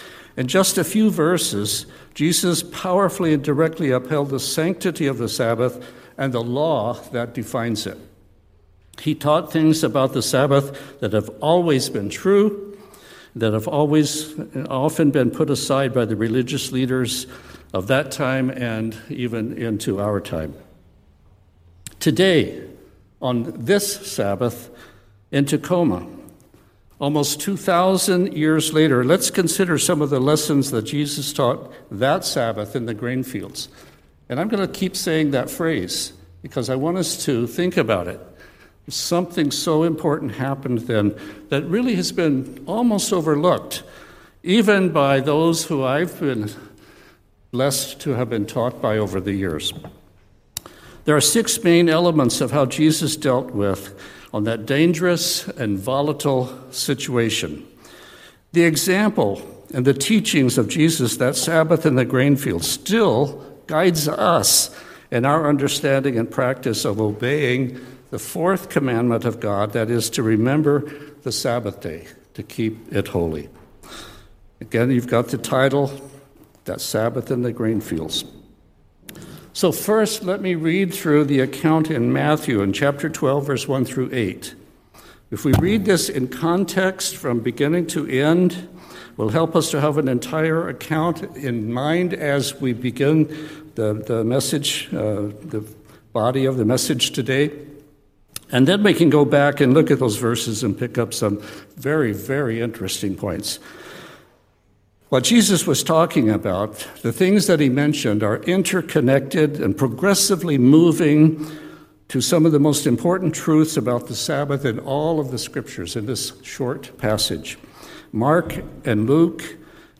Sermons
Given in Tacoma, WA Olympia, WA